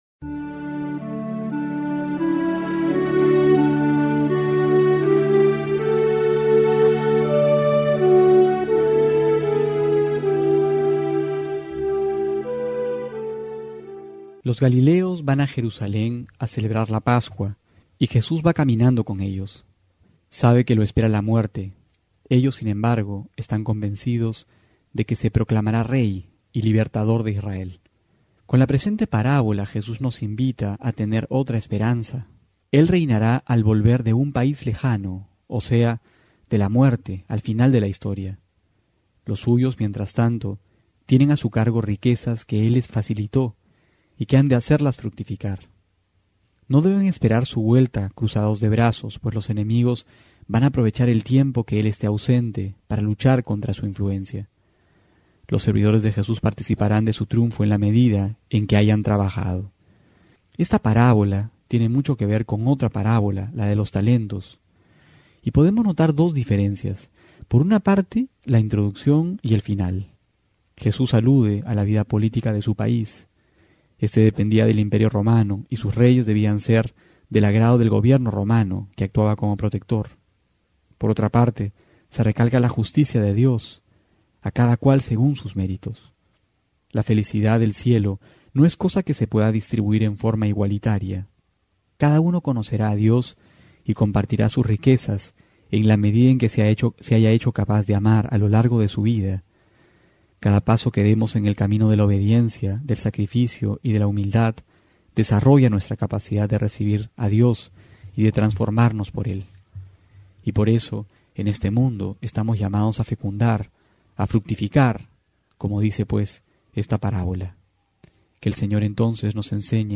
Homilía para hoy: Lucas 19,11-28
noviembre16-11homilia.mp3